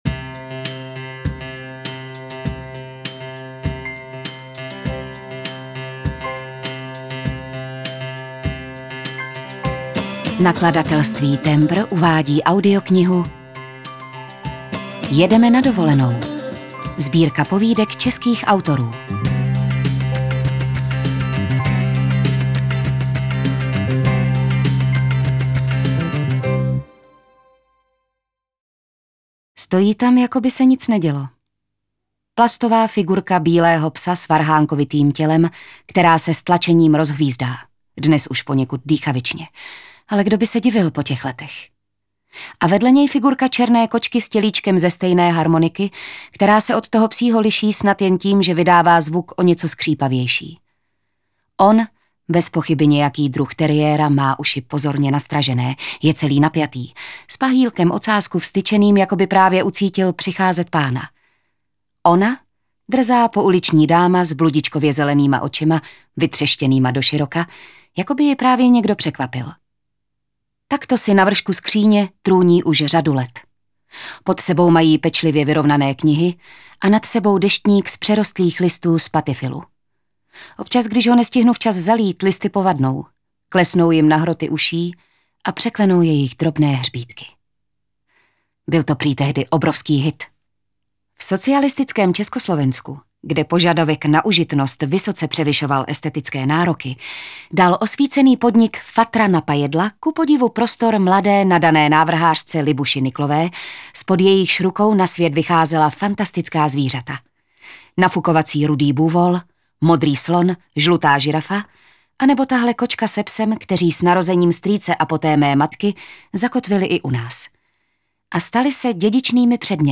Jedeme na dovolenou - Markéta Pilátová, Kateřina Tučková, Petra Soukupová, Jakuba Katalpa, Petra Dvořáková, Boris Dočekal, Viktorie Hanišová, Dora Čechova, Anna Bolavá, Alice Nellis - Audiokniha